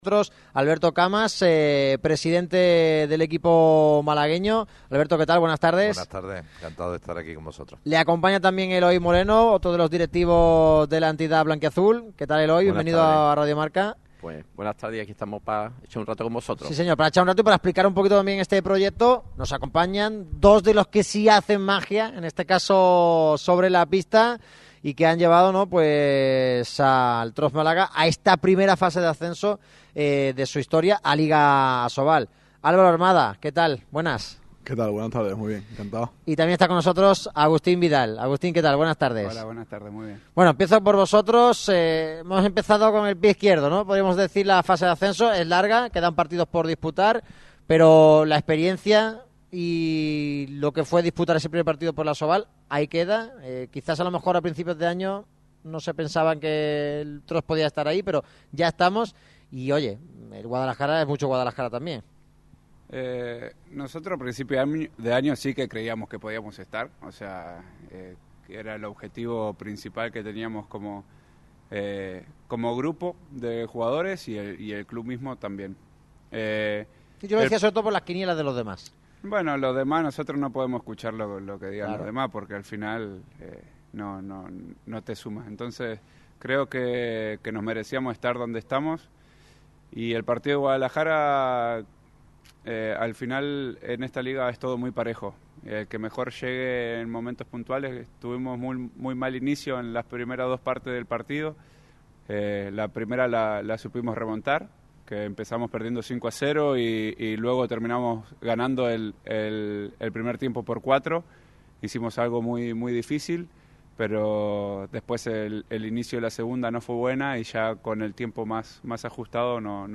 durante la entrevista Facebook Twitter Pinterest WhatsApp Linkedin Telegram El TROPS Málaga es uno de los equipos de moda en la capital de la Costa del Sol.